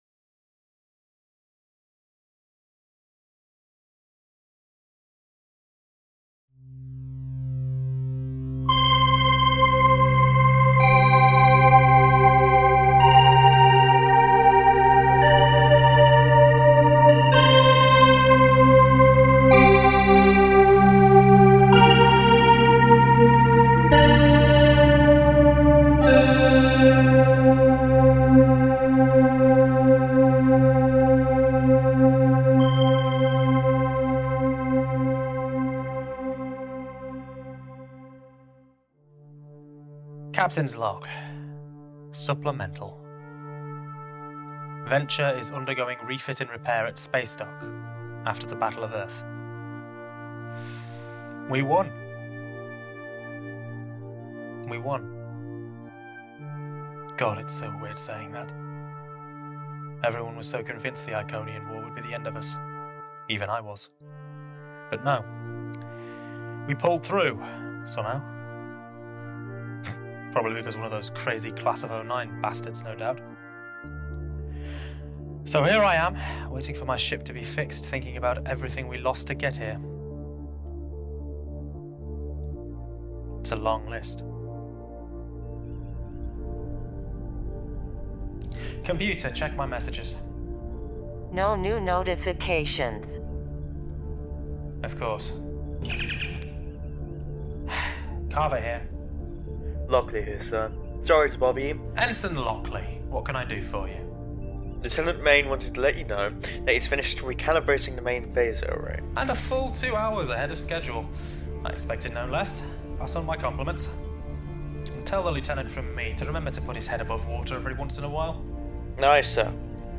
Audio Books/Drama